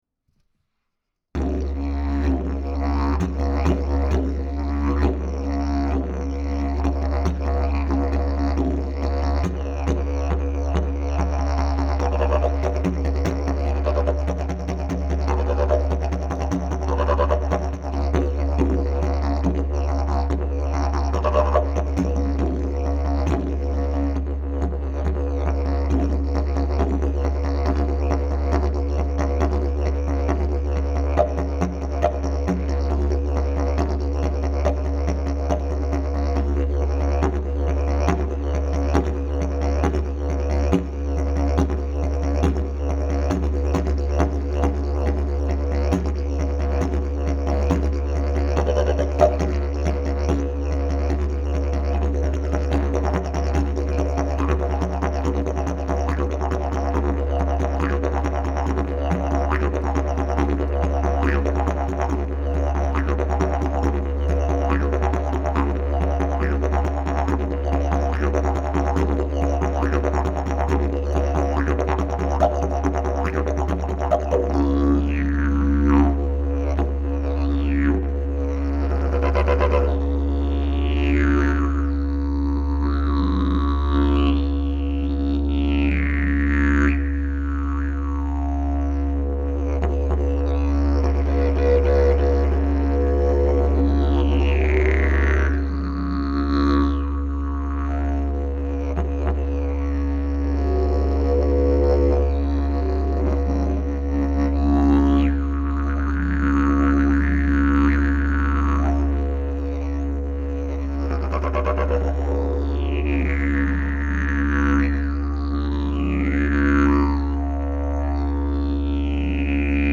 Key: D Length: 62" Bell: 4.75" Mouthpiece: Red Zebrawood, Sapele Back pressure: Very strong Weight: 3 lbs Skill level: Any
Didgeridoo #630 Key: D